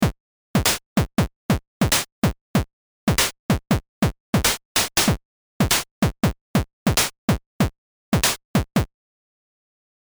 バスドラム その2 （三角波）
基本の波形を矩形波から三角波に変えることで、かなりマイルドな音色になってきます。